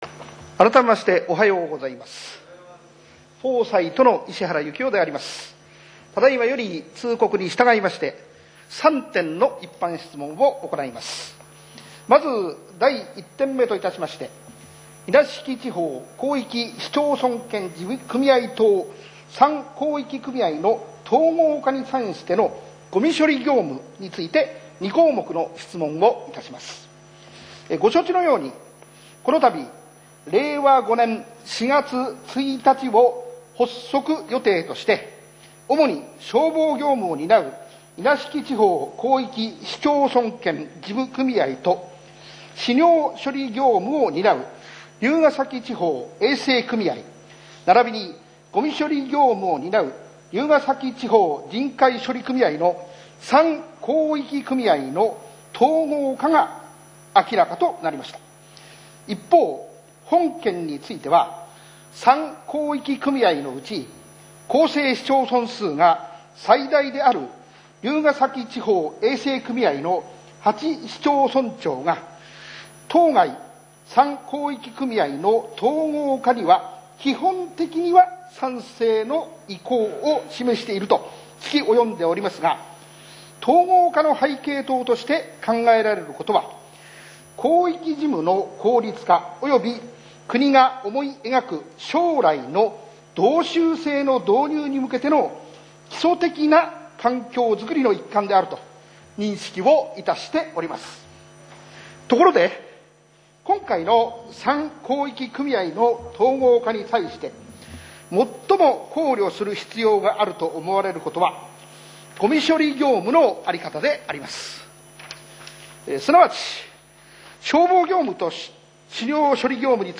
令和3年第3回定例会 1番 石原議員 | 牛久市公式ホームページ